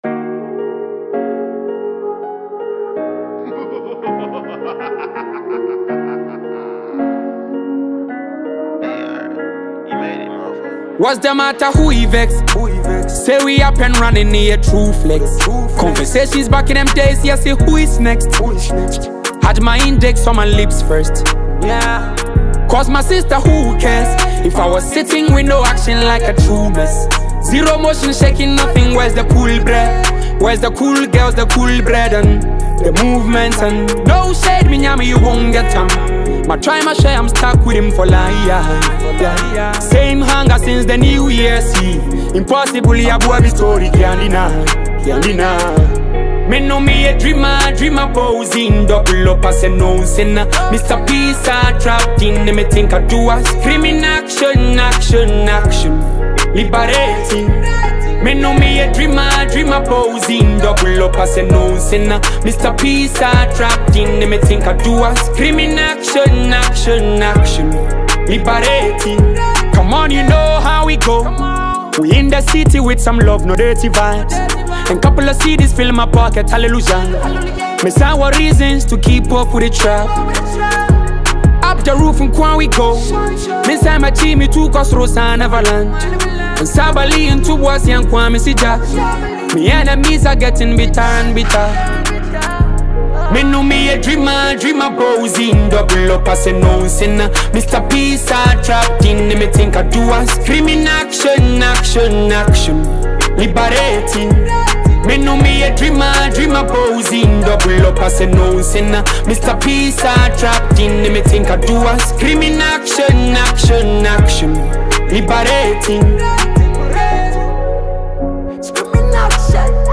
Afrobeat
which offers a hypnotic atmosphere that draws listeners in.
emotive delivery
melancholic melody